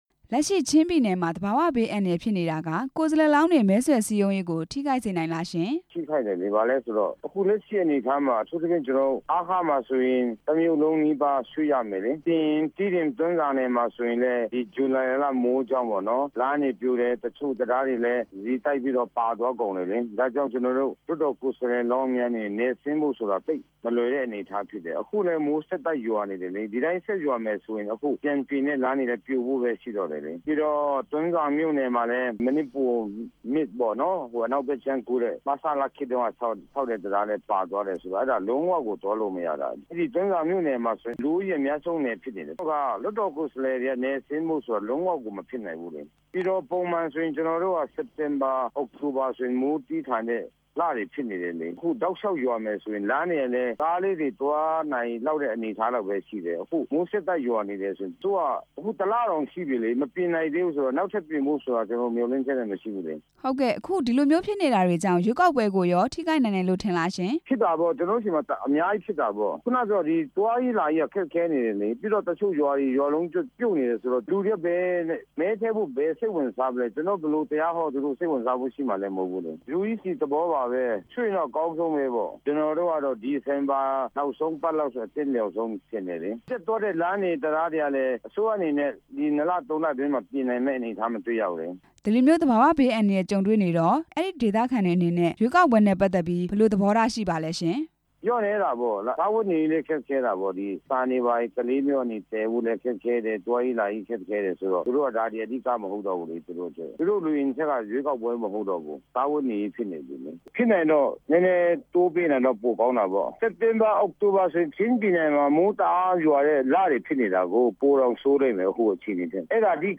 ချင်းပြည်နယ် ရွေးကောက်ပွဲနဲ့ ပတ်သက်ပြီး ဇိုမီးဒီမိုကရေစီ အဖွဲ့ချုပ်ပါတီကို မေးမြန်းချက်